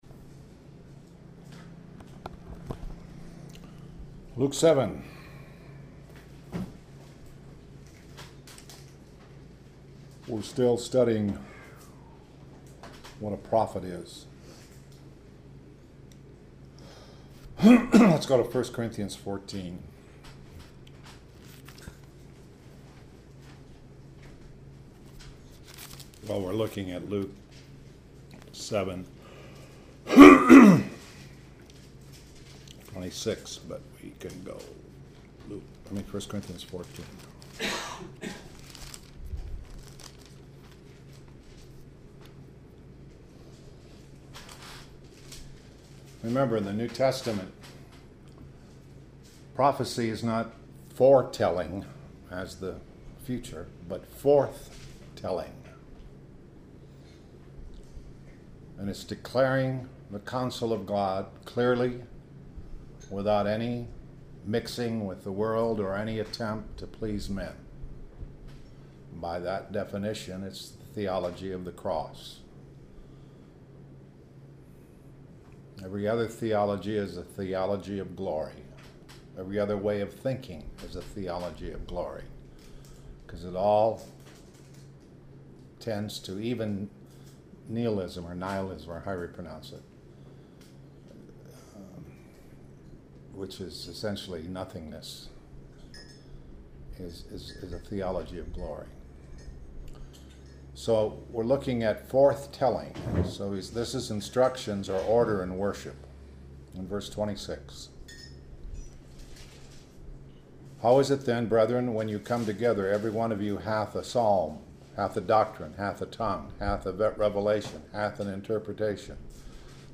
← Gospel of Luke 7:26-July 28, 2011 Gospel of Luke 7:27-August 01, 2011 → Gospel of Luke 7:26-27-July 29, 2011 Posted on July 29, 2011 by admin Gospel of Luke 7:26-27-July 29, 2011 This entry was posted in Morning Bible Studies .